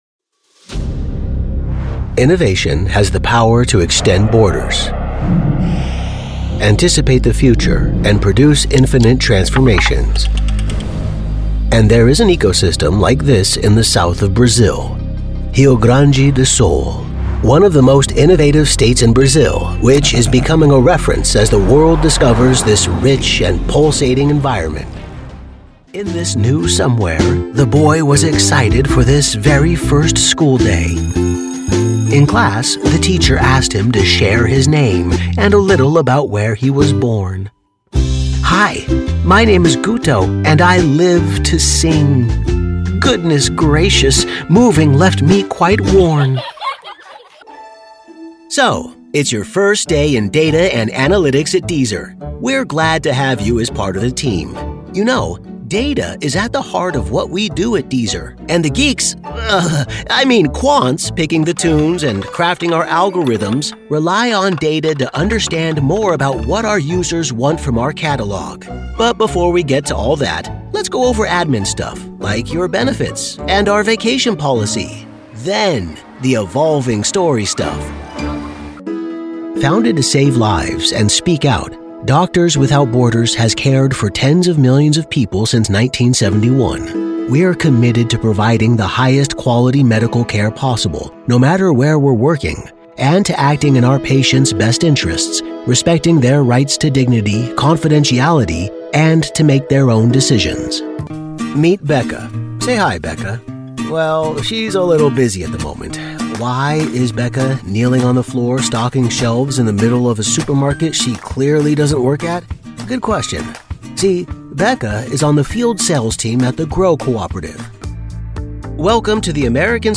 eLearning Demo
Middle Aged
Friendly | Conversational | Genuine